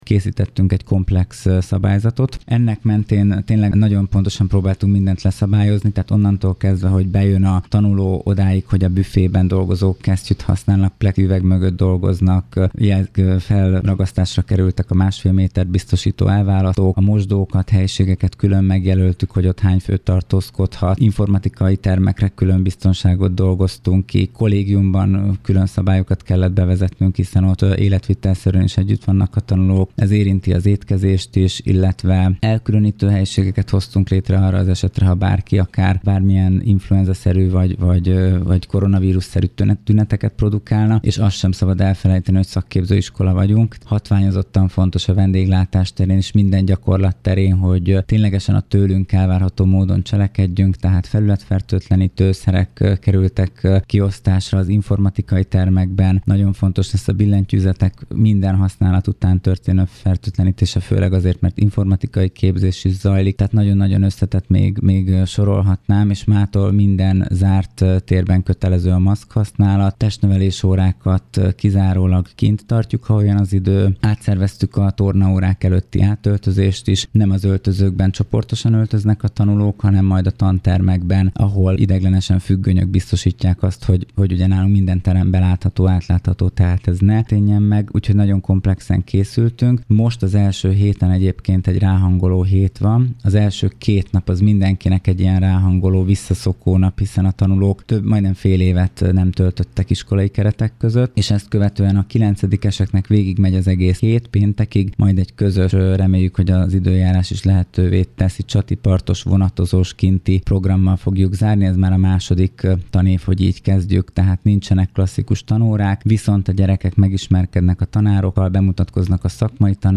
Dr. Kendéné Toma Mária Kakucs polgármestere kifejezett örömét fejezte ki a tervezett fejlesztéssel kapcsolatban, illetve további tervezett fejlesztésekről is beszélt rádiónknak, ami a teherszállítást érinti majd elsősorban. A következő percekben polgármesterasszonyt hallják: